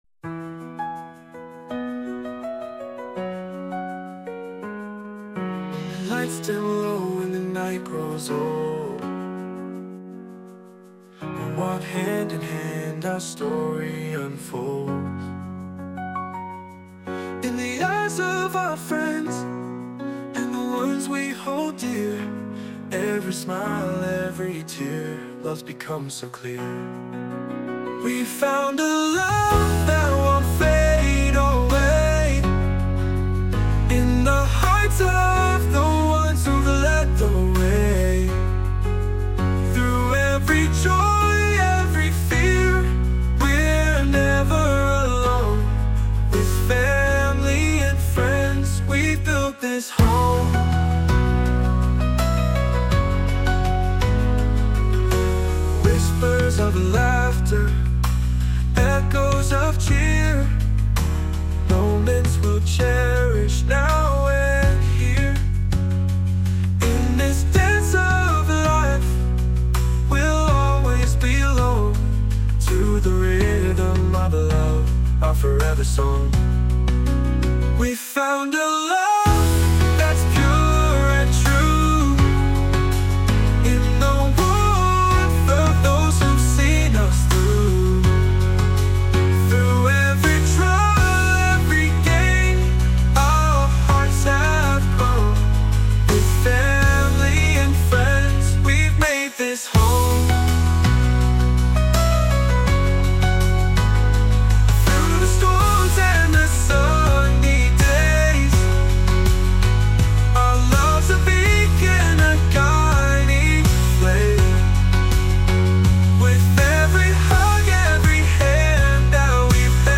洋楽男性ボーカル著作権フリーBGM ボーカル
男性ボーカル（洋楽・英語）曲です。